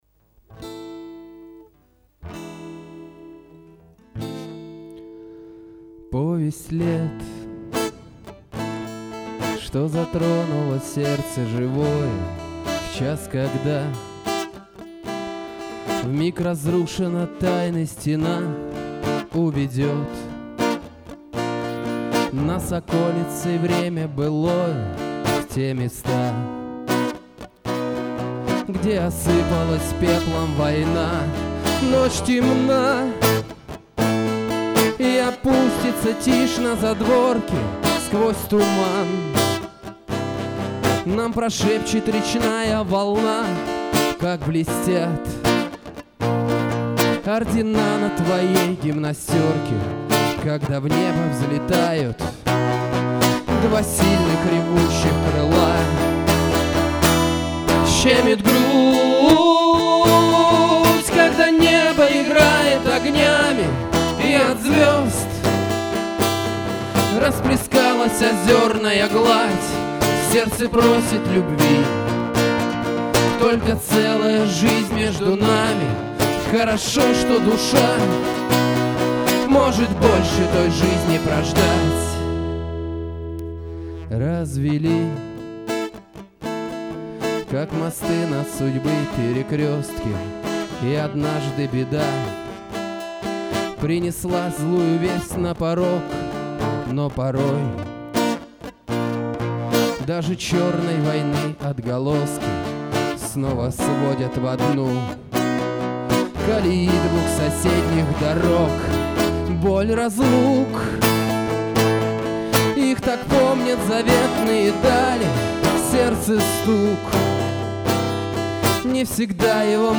Авторская песня / Читателей: 21